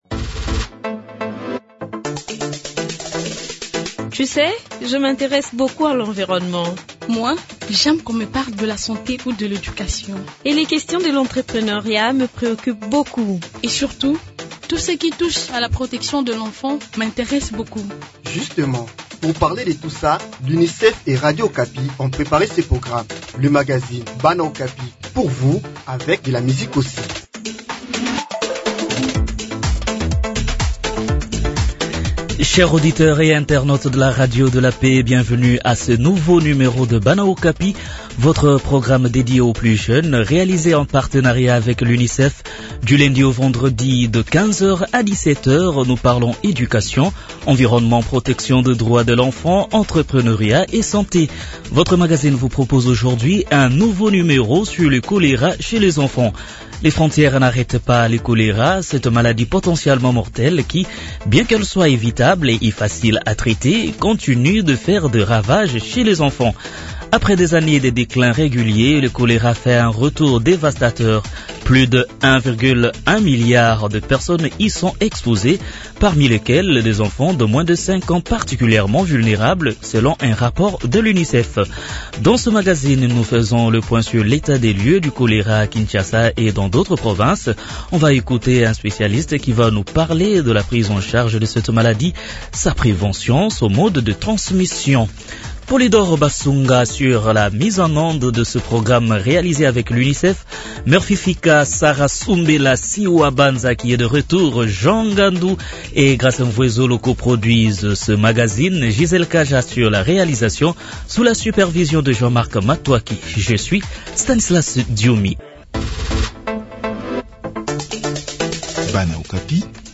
Dans ce magazine nous faisons les points sur l’état de lieu du cholera à Kinshasa et dans d’autres provinces de la RDC. Un spécialiste va parler de la prise en charge de cette maladie, sa prévention, ainsi que son mode de transmission.